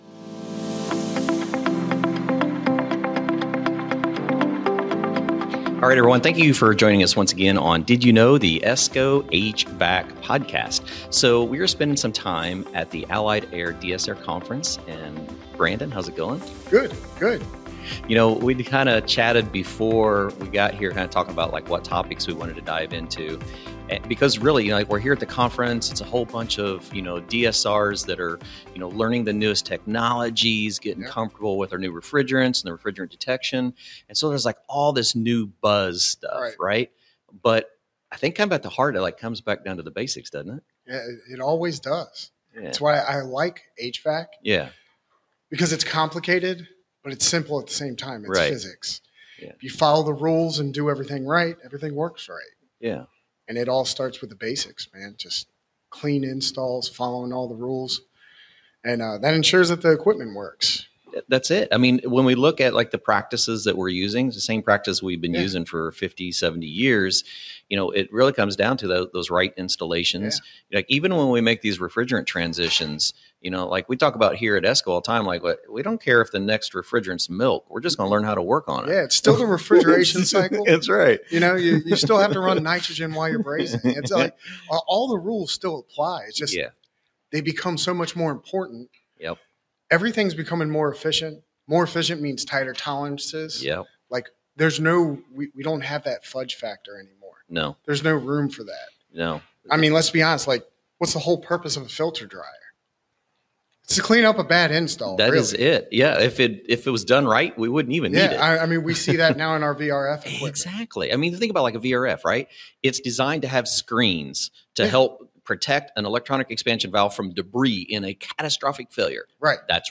In this engaging episode, we broadcast from the 2025 Allied Air DSR Technical Training Conference to discuss the evolving HVAC landscape and the critical importance of mastering the fundamentals.